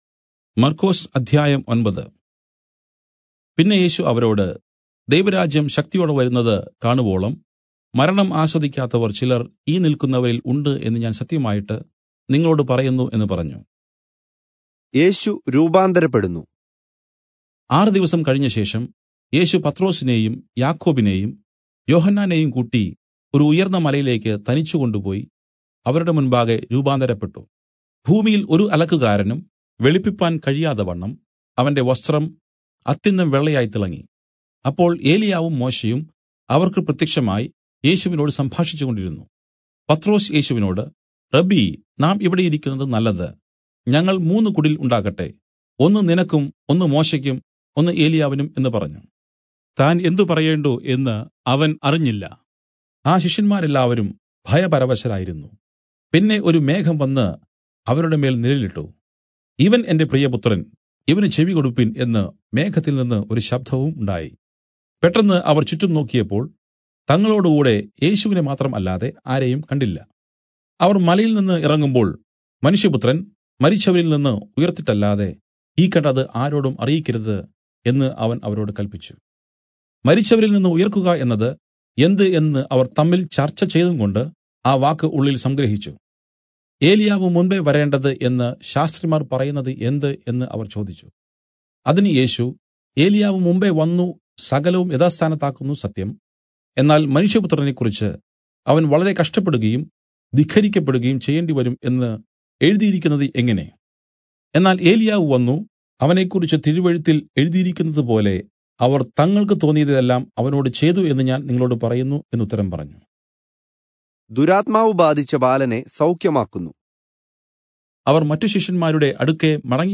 Malayalam Audio Bible - Mark 14 in Irvml bible version